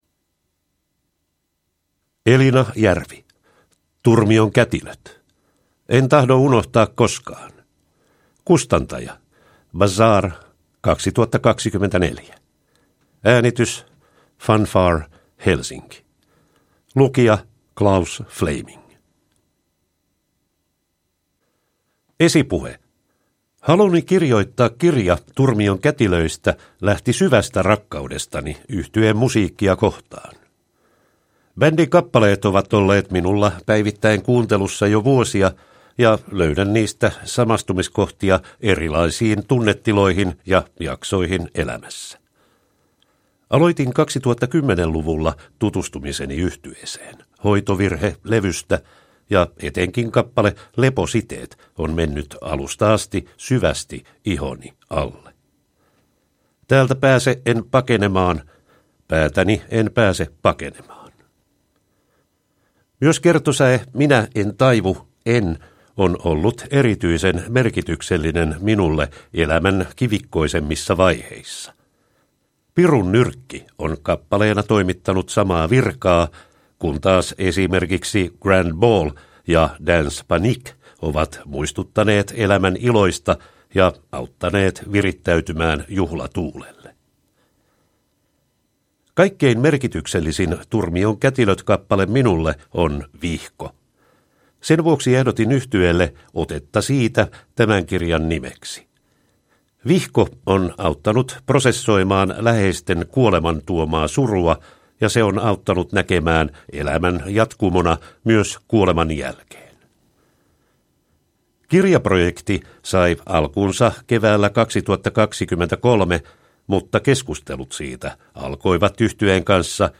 Turmion kätilöt: En tahdo unohtaa koskaan – Ljudbok